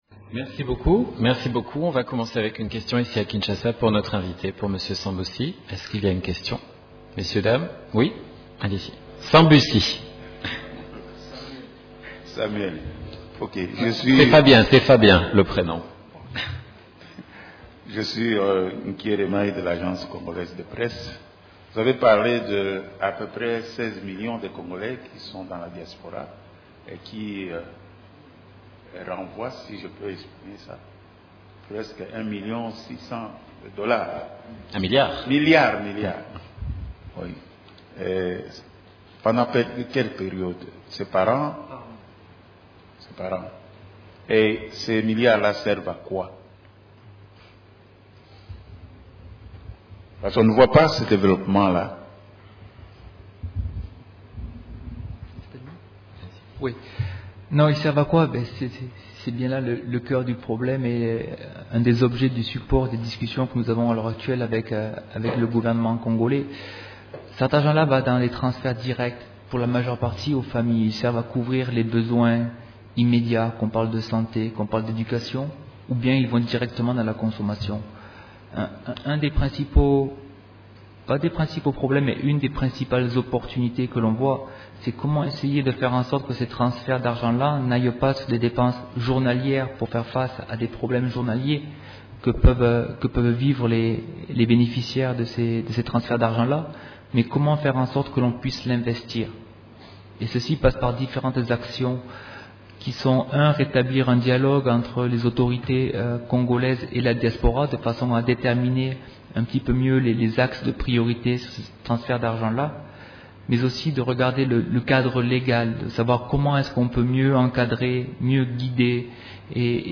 Conférence de presse de l'ONU à Kinshasa du mercredi 18 décembre 2019